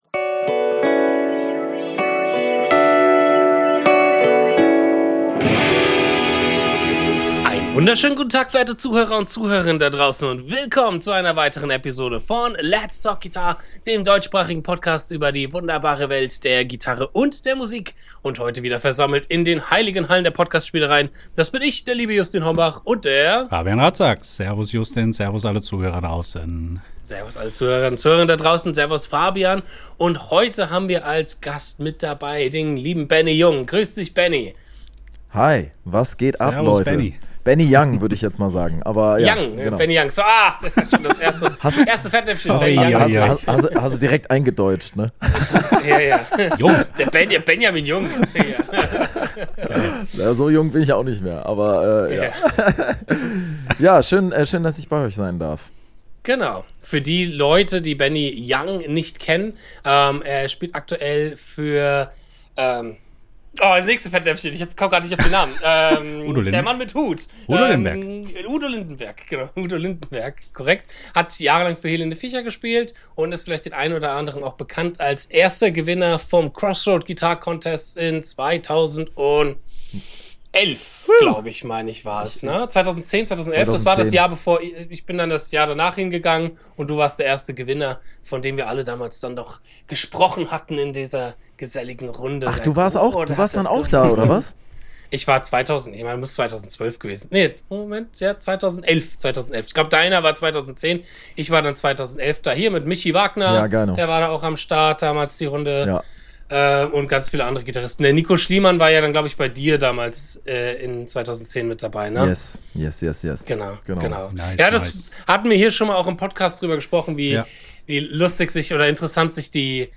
Episode 170: Mit Udo Lindenberg unterwegs - Interview